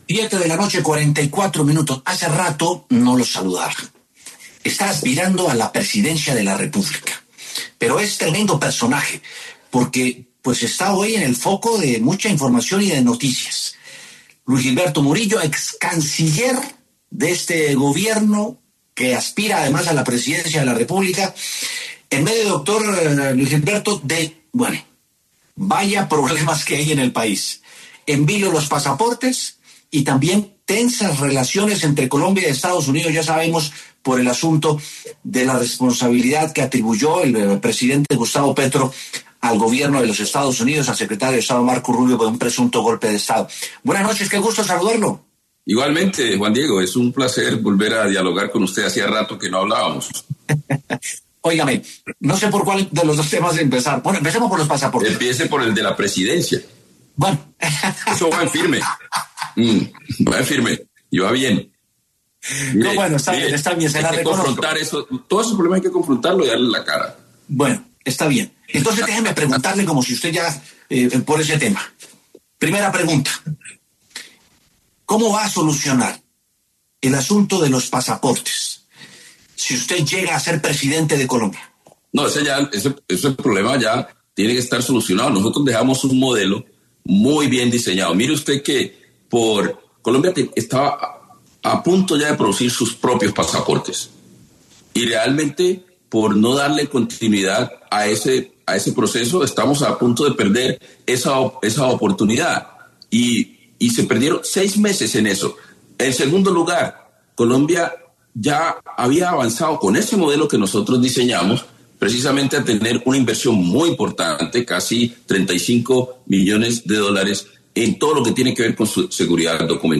El excanciller y ahora precandidato presidencial, Luis Gilberto Murillo, pasó por los micrófonos de W Sin Carreta para hablar acerca del lío que tiene el Gobierno Petro para la fabricación de pasaportes.